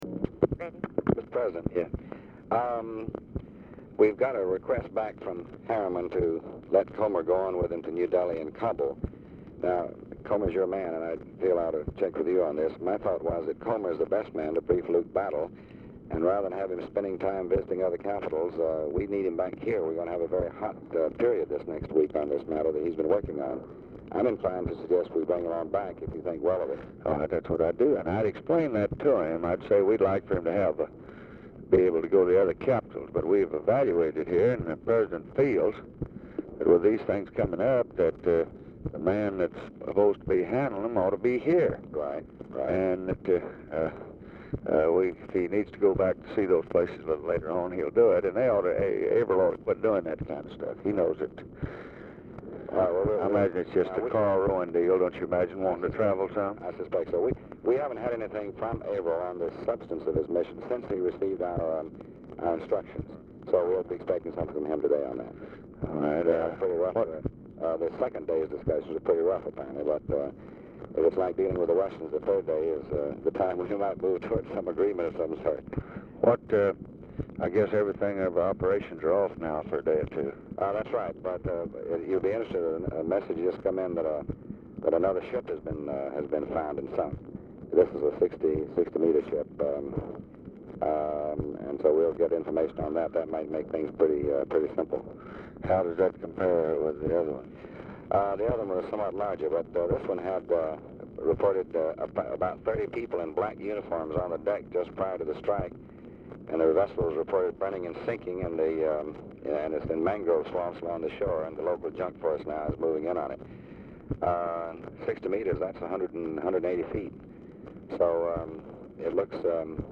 Telephone conversation # 6892, sound recording, LBJ and DEAN RUSK, 2/27/1965, 11:23AM | Discover LBJ
Format Dictation belt
Location Of Speaker 1 Oval Office or unknown location
Specific Item Type Telephone conversation Subject Defense Diplomacy Middle East Vietnam White House Administration